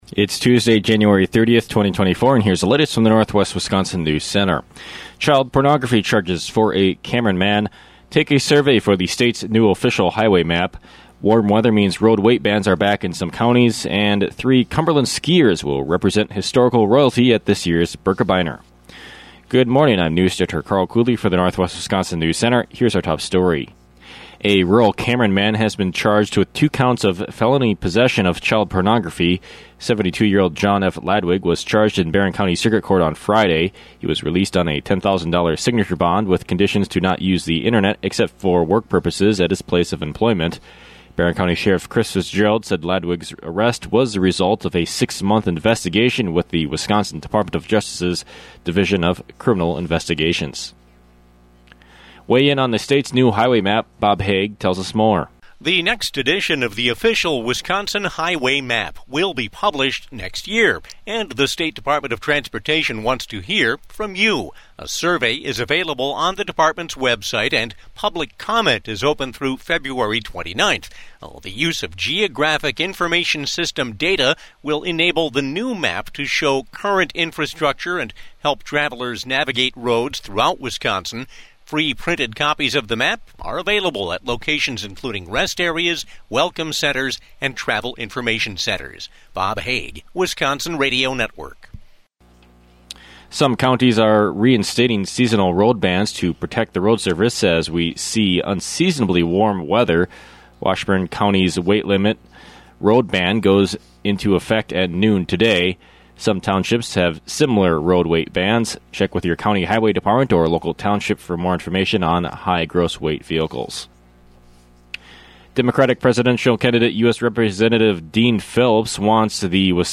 AM NEWSCAST – Tuesday, Jan. 30, 2024